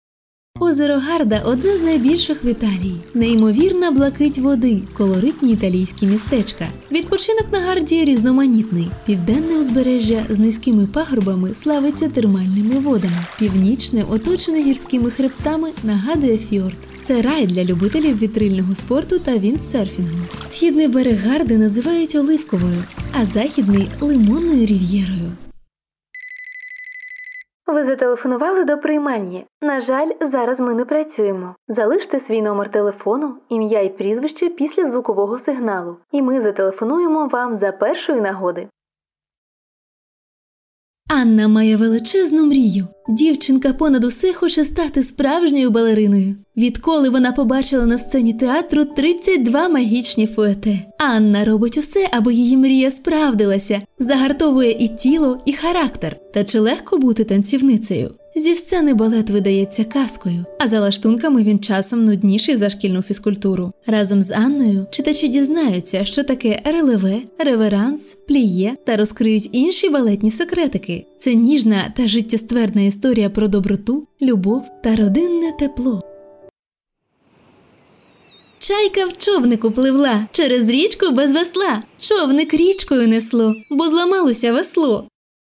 配音风格： 浑厚